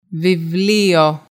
vētă